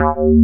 BASS30  01-R.wav